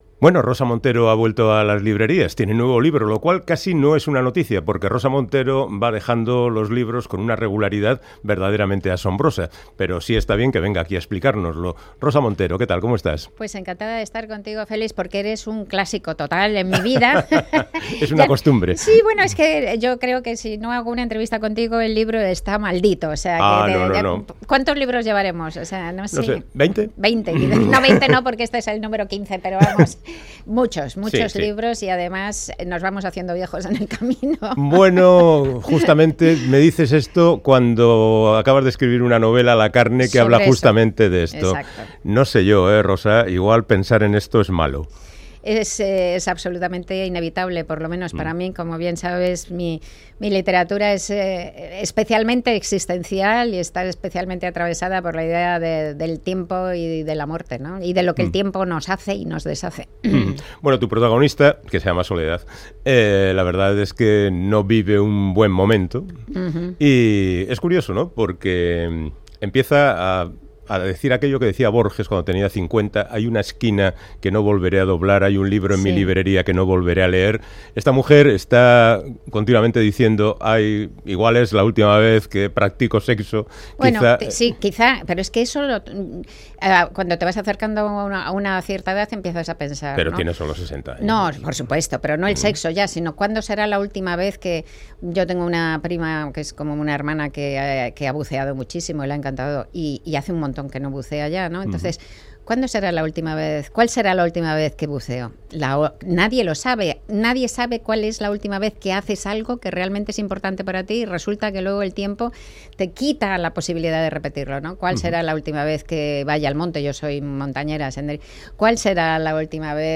Audio: Charlamos con la escritora y periodista madrileña Rosa Montero de su última novela, La carne